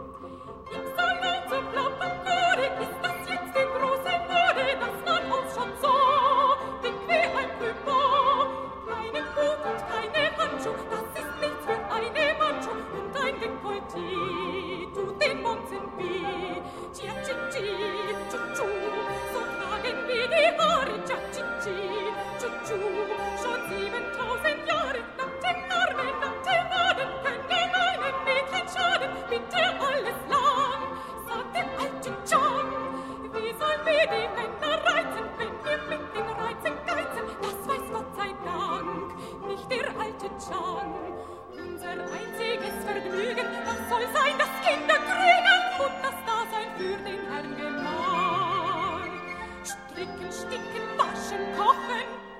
000lehar-ldl-pagode-tanzlied-mi.mp3